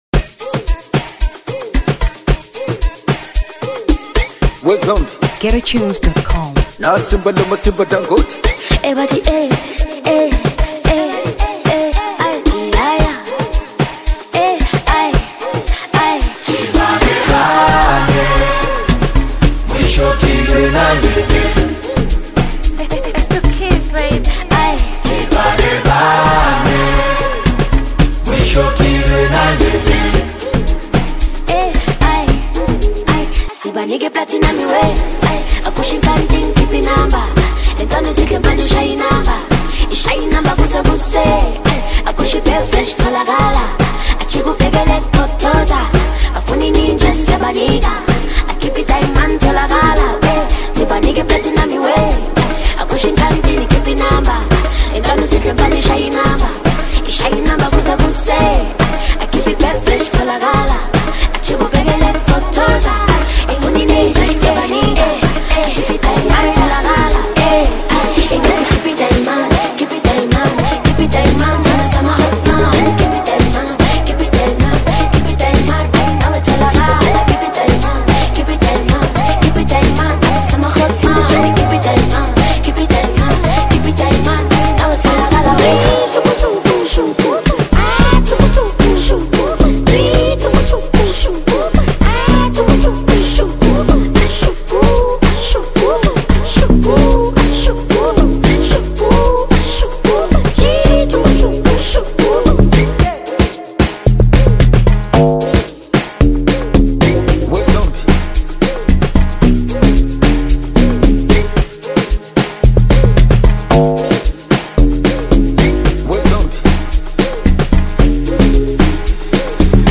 Amapiano 2023 Tanzania